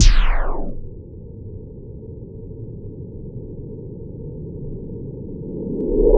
base-slow-down.wav